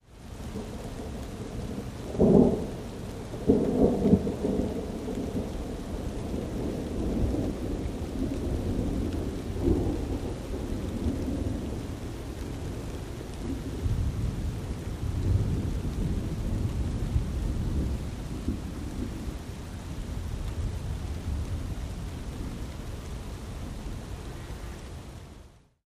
am_rain_thunder_02_hpx
Steady rain with long thunder roll out.